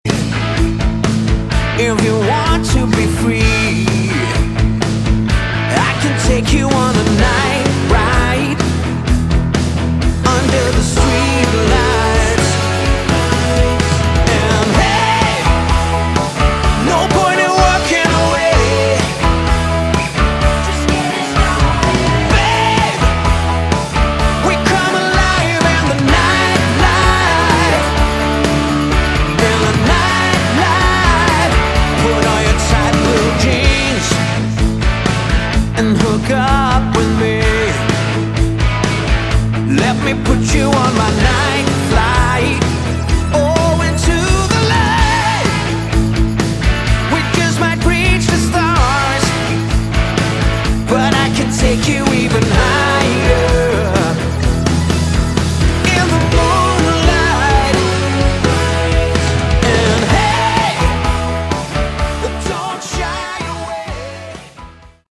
Category: Melodic Rock
lead and backing vocals, bass
guitars, synthesizers, backing vocals, percussion
drums
grand Piano, background vocals
organ